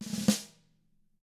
DW2ROLL2+D-L.wav